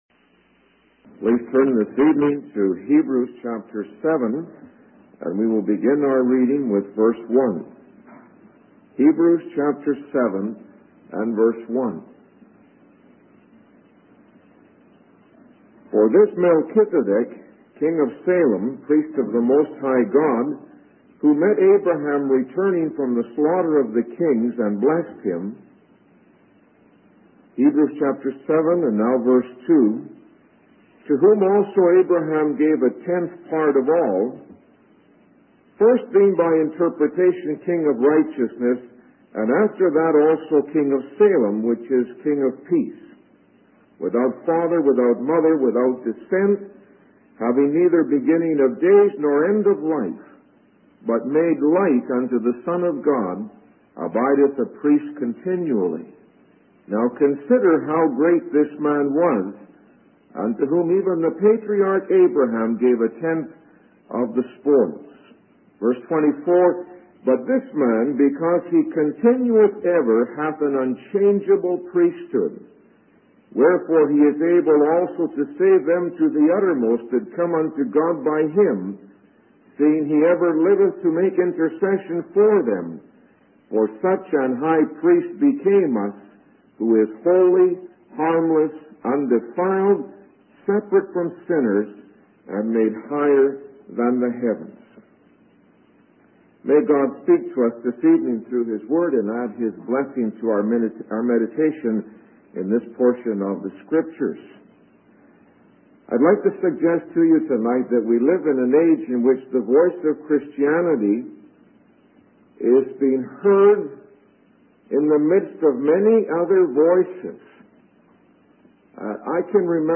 In this sermon, the speaker discusses the importance of the moral character of Jesus in his ability to save people. He starts by sharing a story from the book of Genesis about a mysterious person called Melchizedek.